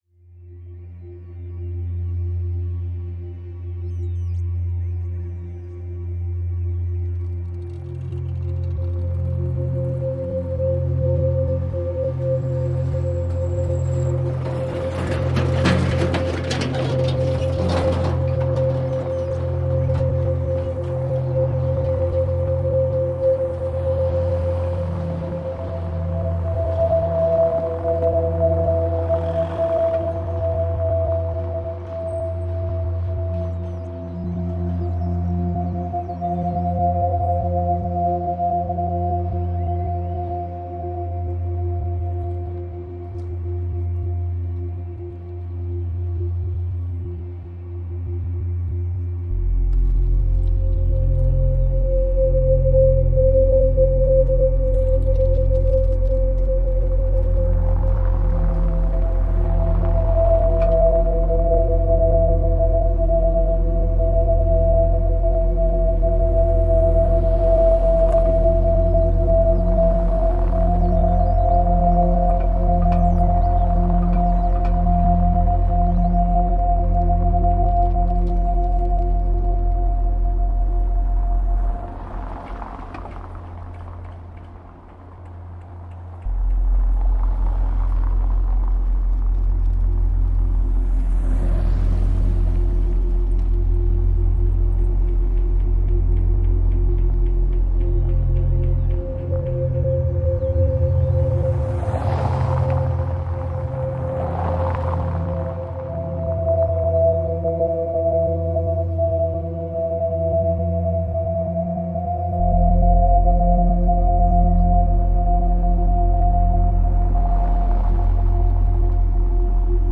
Cobblestones at Leopold de Waelplaats, Antwerp